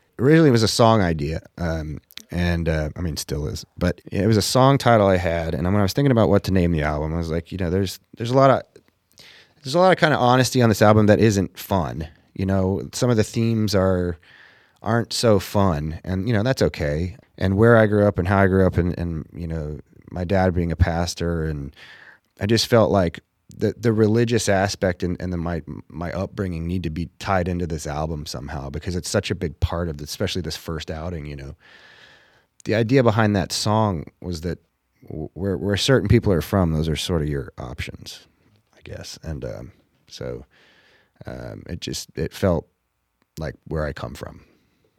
Luke Grimes talks about the title of his EP, "Pain Pills Or Pews."